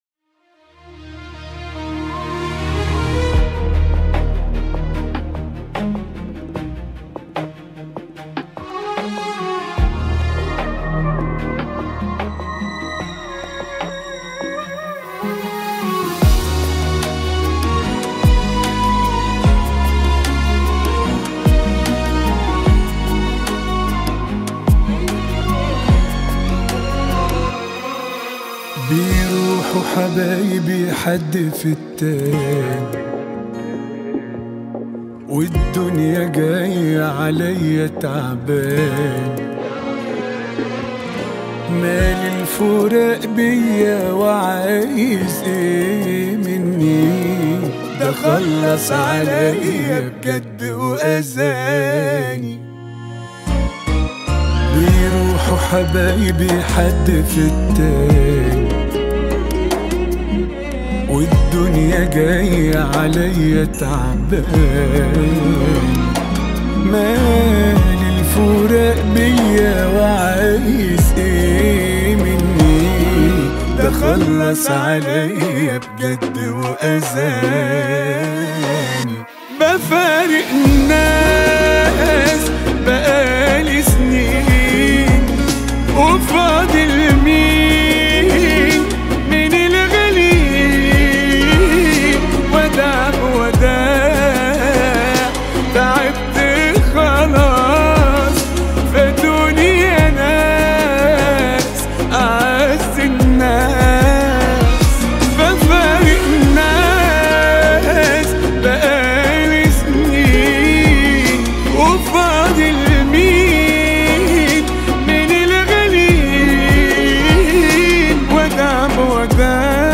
• صوت مليء بالحزن
• أداء صادق
• اغاني حزينة 2026